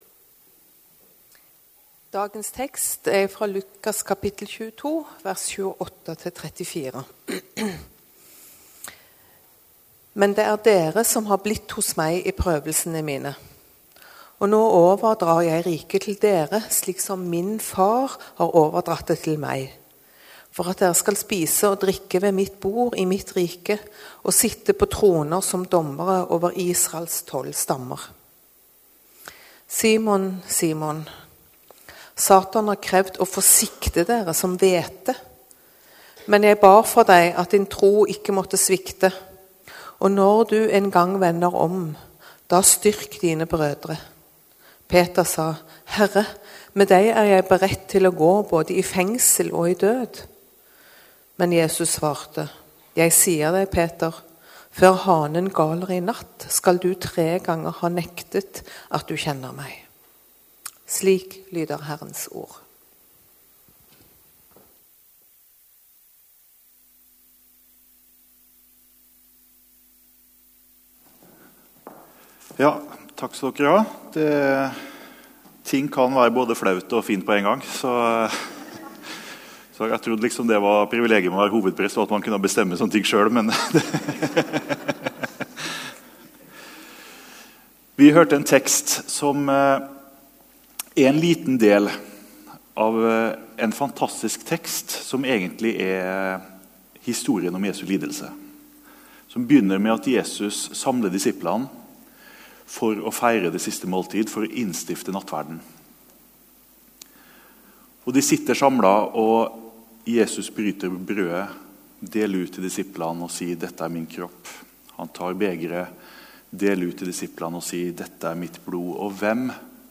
Gudstjeneste 20. mars 2022,-bønn om at troen ikke skal svikte | Storsalen